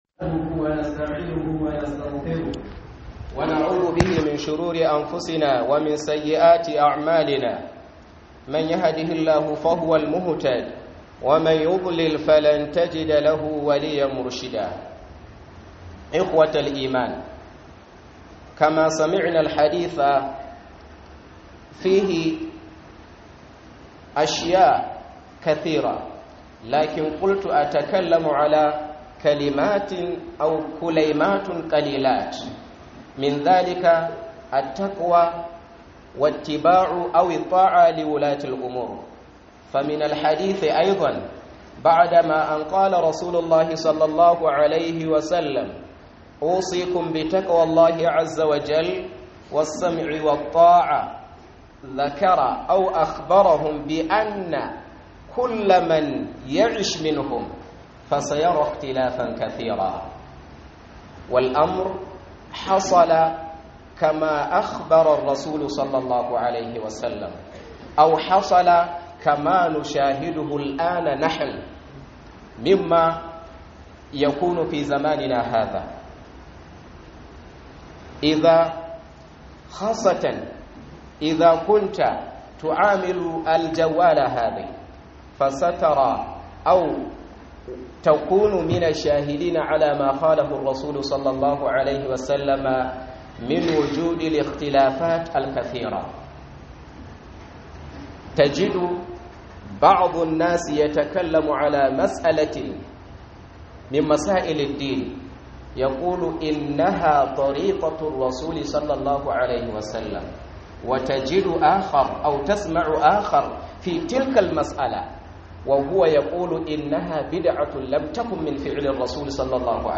KHUDUBAR JUMA'AH